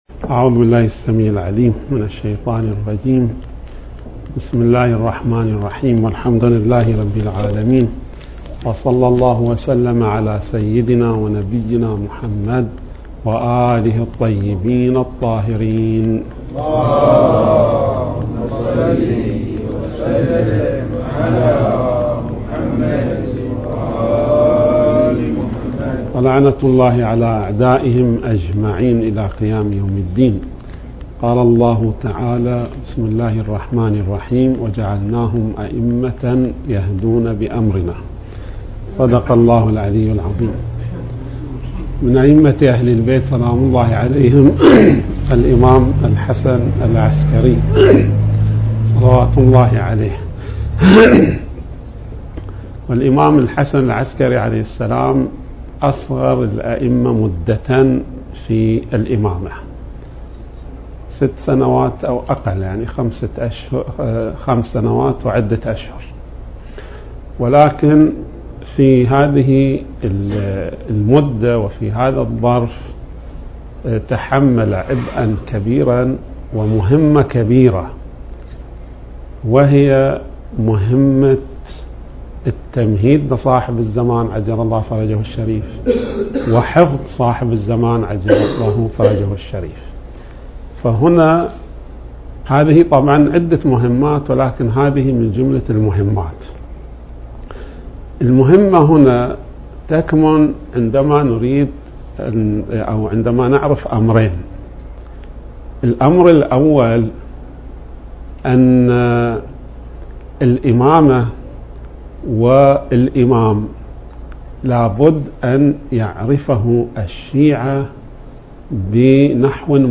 المكان: مسجد العمارة/ القطيف بحث الجمعة التاريخ: 2022